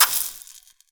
fireball_impact_sizzle_burn4.wav